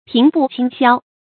平步青霄 注音： ㄆㄧㄥˊ ㄅㄨˋ ㄑㄧㄥ ㄒㄧㄠ 讀音讀法： 意思解釋： 見「平步青云」。